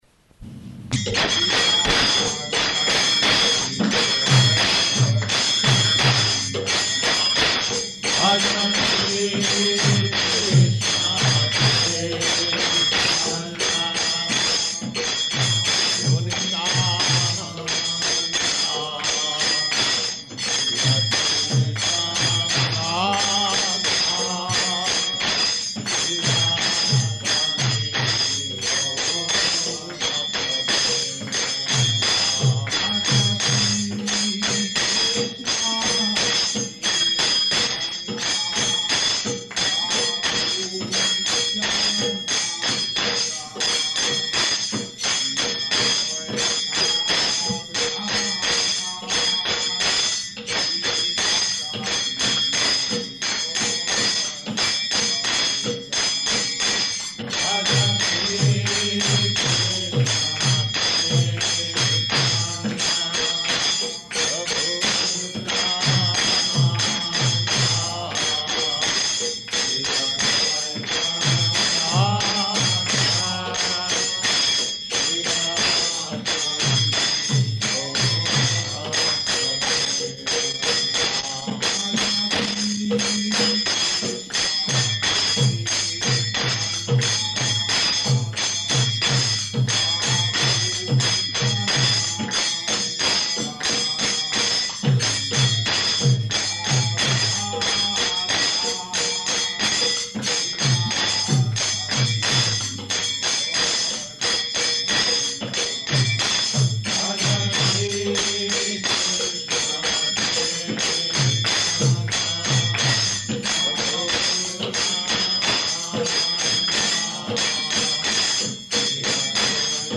Lecture, Questions and Answers
Lecture, Questions and Answers --:-- --:-- Type: Lectures and Addresses Dated: March 23rd 1969 Location: Hawaii Audio file: 690323LE-HAWAII.mp3 [ kīrtana ] [ prema-dhvani ] All glories to the assembled devotees.